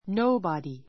nobody 中 A2 nóubɑdi ノ ウバディ ｜ nóubɔdi ノ ウボディ 代名詞 誰 だれ も～ない （no one） ⦣ 単数扱 あつか い. Nobody knows it.